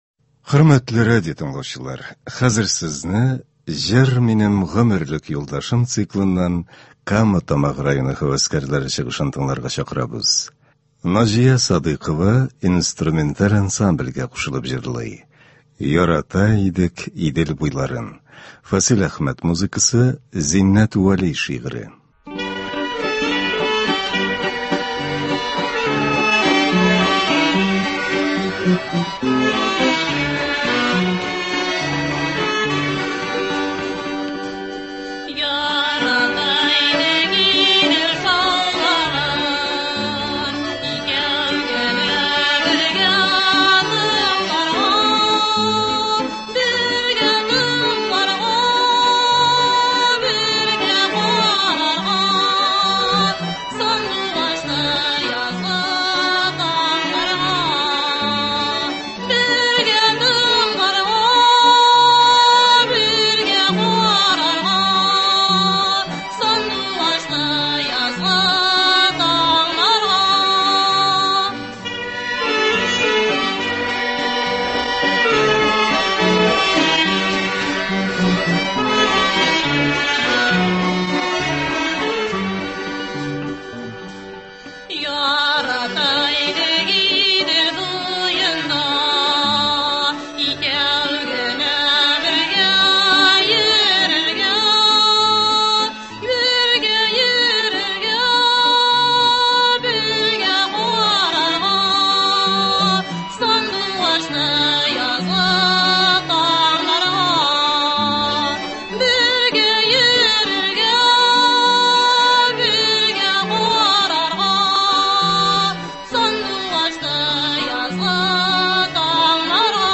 Концерт (17.10.22)